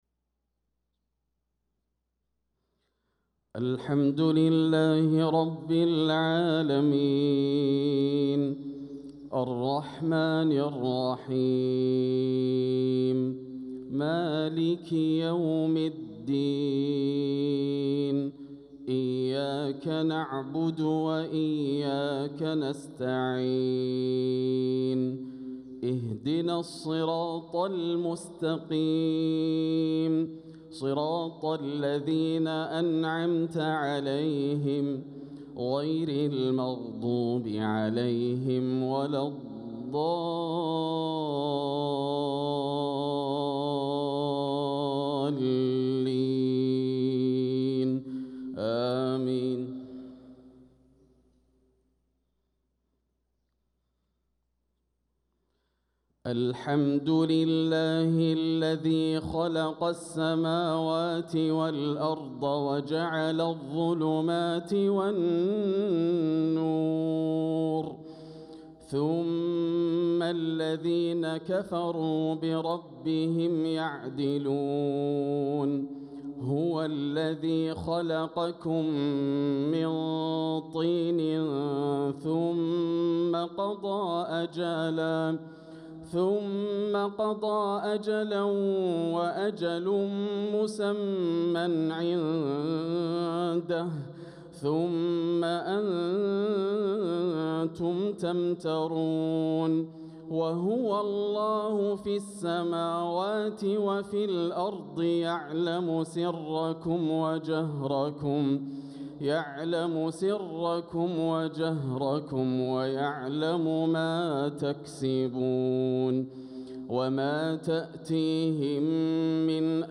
صلاة الفجر للقارئ ياسر الدوسري 1 صفر 1446 هـ